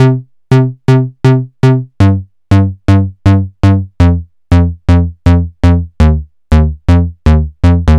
TSNRG2 Bassline 004.wav